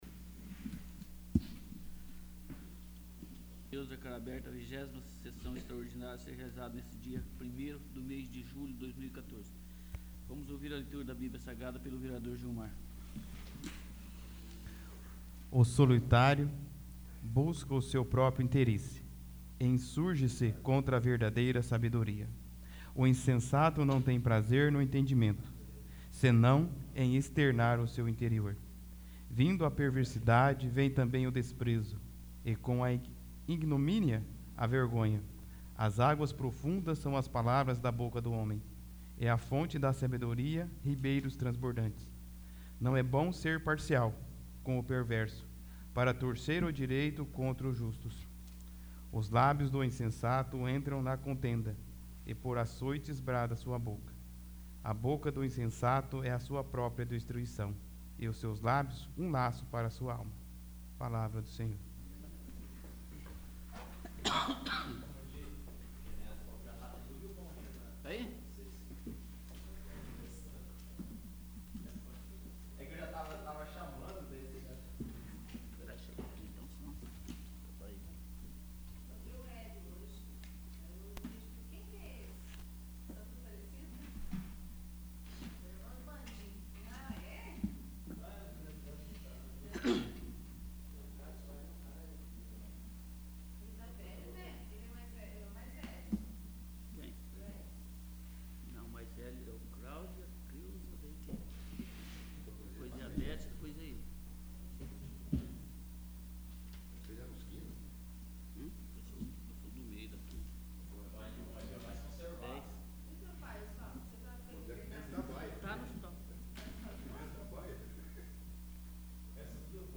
20º. Sessão Extraordinária